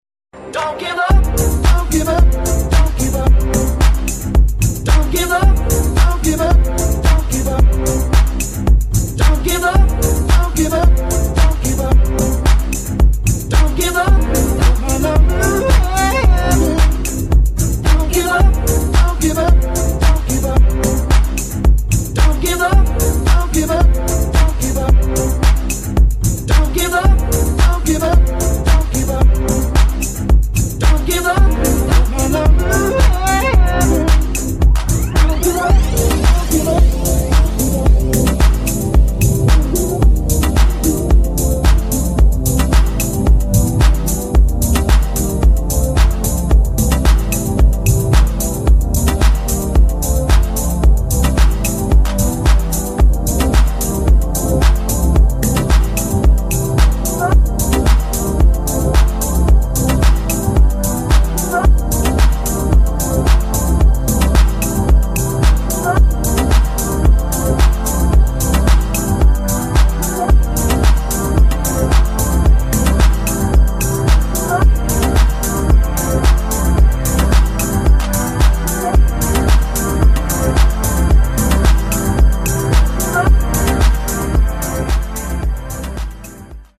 HOUSE | DEEP HOUSE